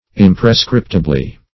Search Result for " imprescriptibly" : The Collaborative International Dictionary of English v.0.48: Imprescriptibly \Im`pre*scrip"ti*bly\, adv.